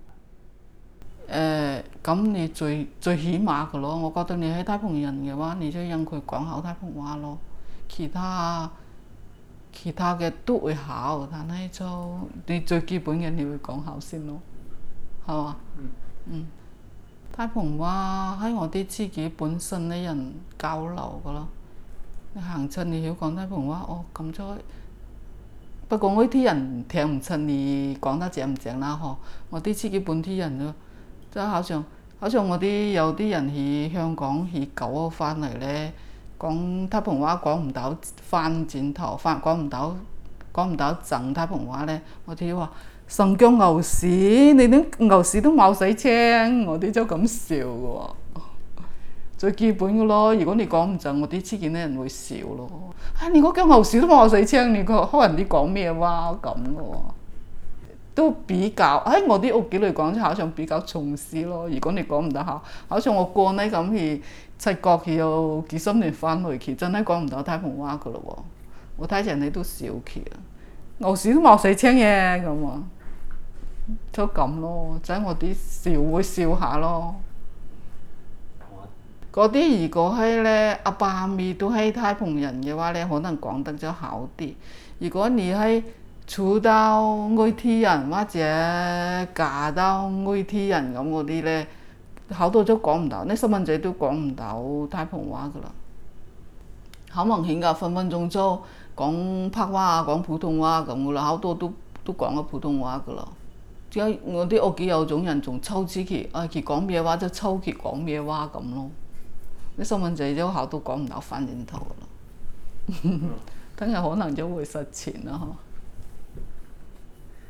Female, 48
digital wav file recorded at 44.1 kHz/16 bit on Zoom H2 solid state recorder
Dapeng, Shenzhen, Guangdong Province, China
Dapeng dialect in Shenzhen, China